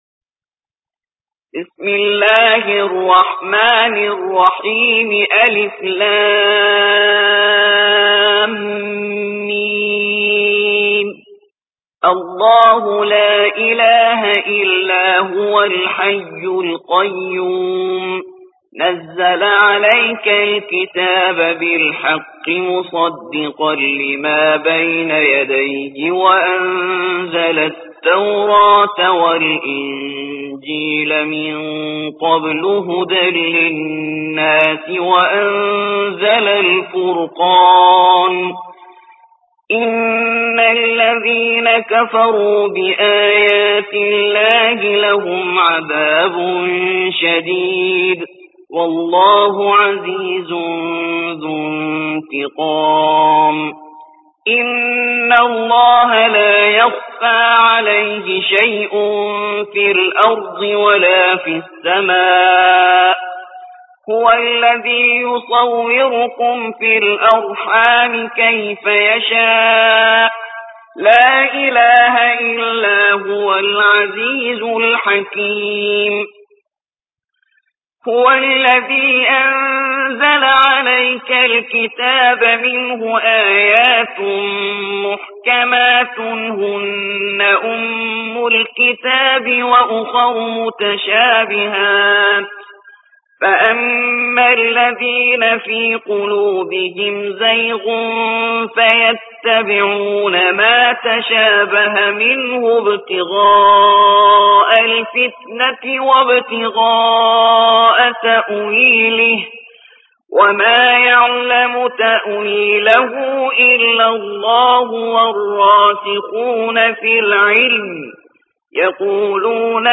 3. سورة آل عمران / القارئ